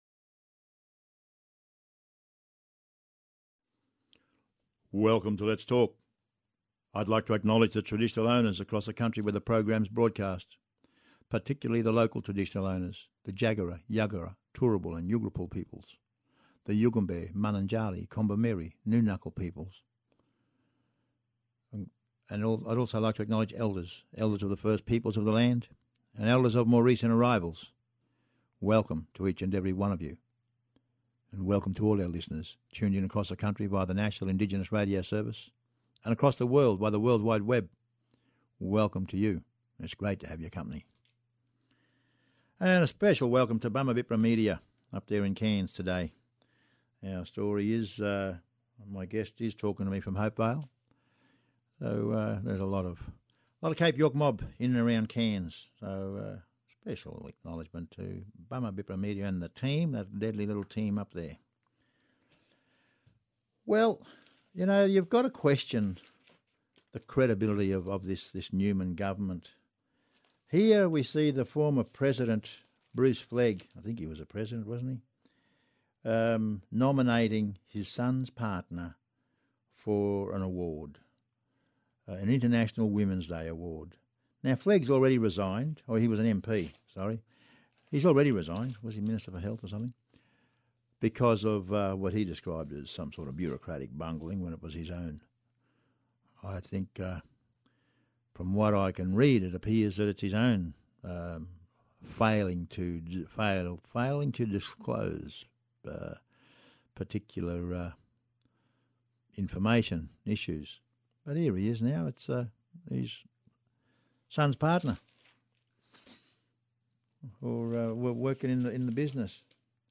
Mayor of Hopevale speaking about the Police mishandling of community conflict, welfare reform trials and the families responsibilities commission.